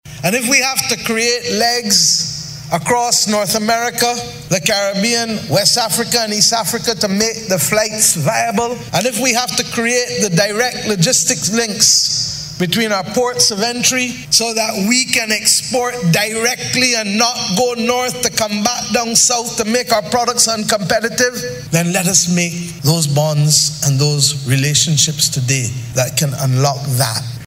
She was delivering the keynote address during the opening ceremony of the 2022 AfriCariibean Trade and Investment Forum at the Lloyd Erskine Sandiford Centre this morning.